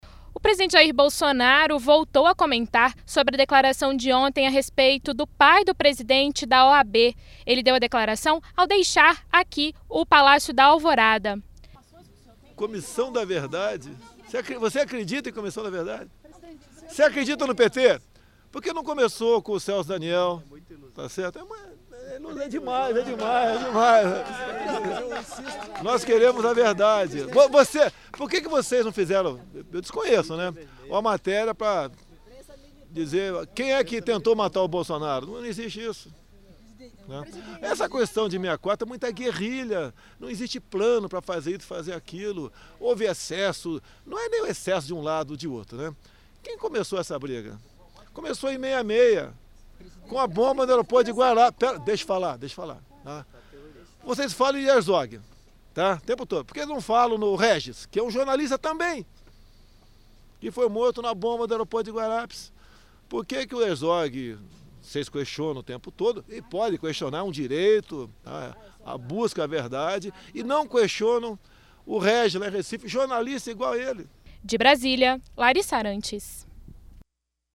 Nesta terça-feira, 30, presidente Jair Bolsonaro falou novamente sobre mortes ocorridas no período da ditadura militar, no Brasil. O presidente conversou com jornalistas no Palácio da Alvorada, agora há pouco.